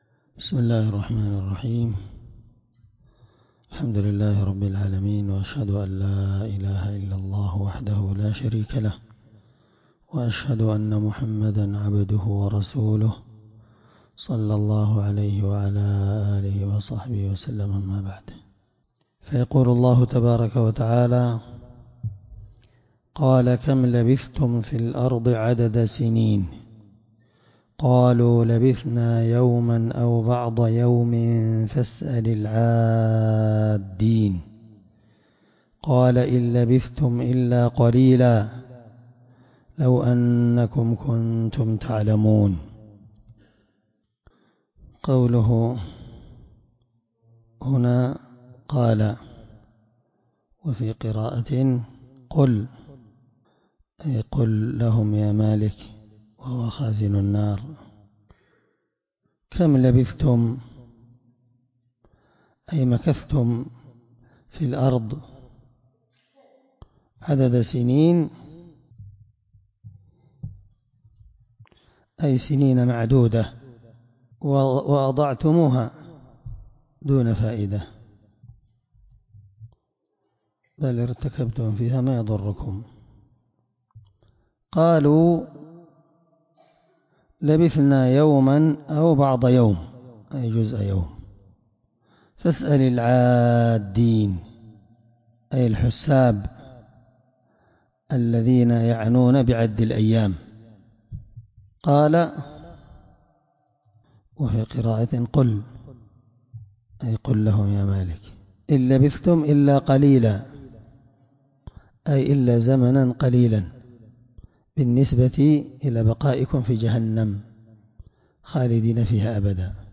الدرس27تفسير آية (114-118) من سورة المؤمنون